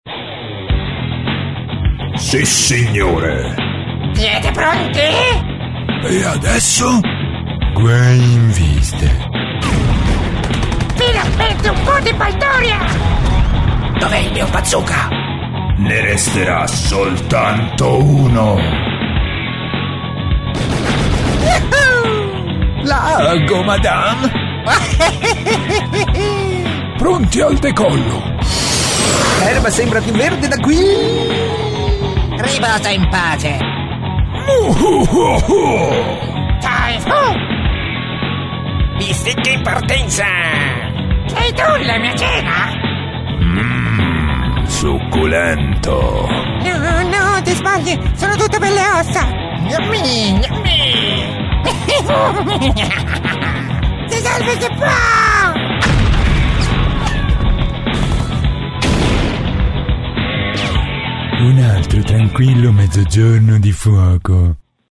The versatility of his voice enables him to provide many varying styles, accents and tones from mild sophisticated and corporate to a rich and sexy.
Sprecher italienisch.
Sprechprobe: Sonstiges (Muttersprache):
italian voice over artist.